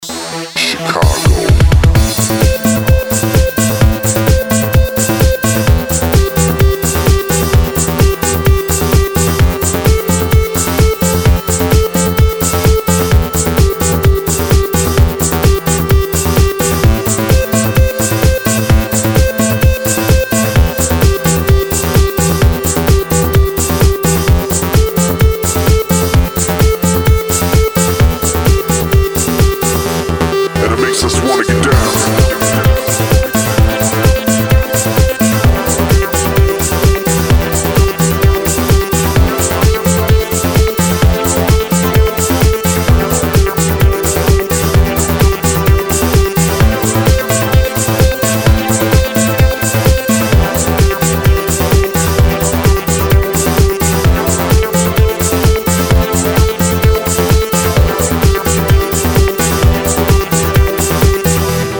Electronic
EDM
progressive house
electro house
Приятная клубная музыка